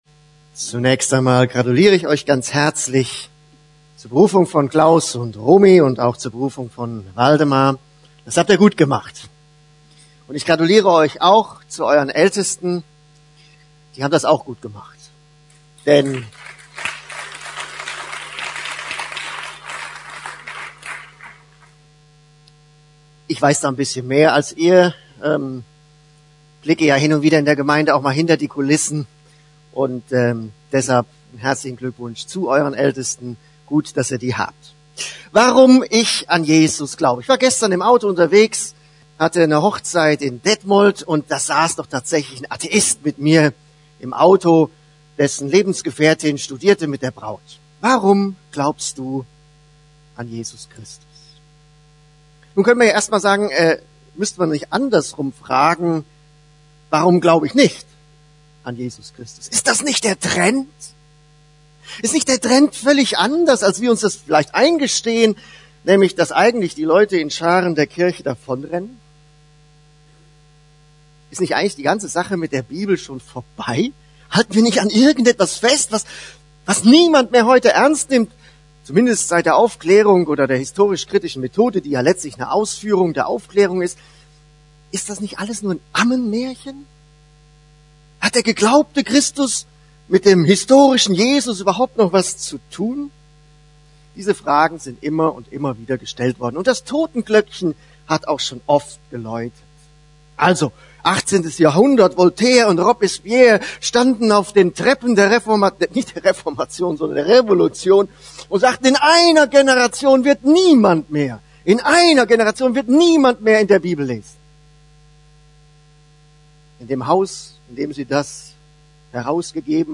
Evangelistische Predigt